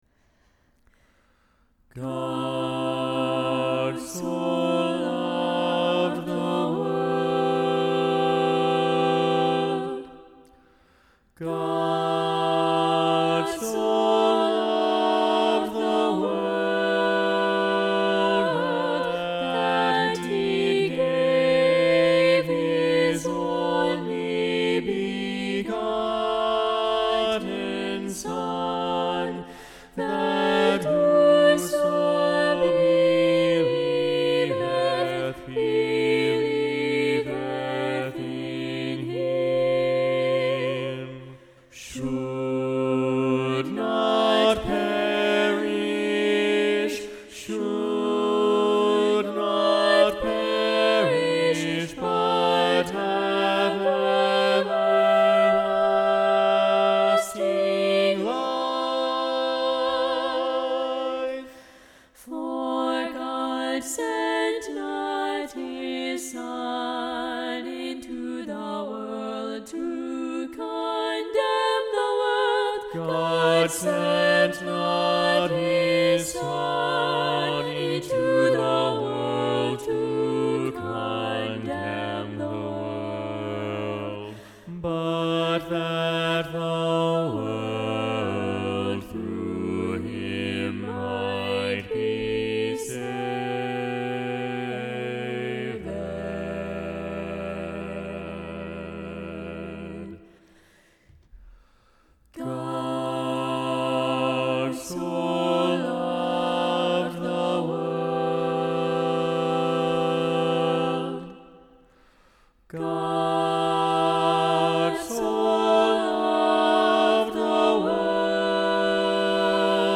God so Loved the World SATB – Tenor Muted – John StainerDownload